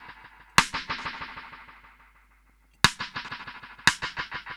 Index of /musicradar/dub-drums-samples/105bpm
Db_DrumsA_SnrEcho_105_03.wav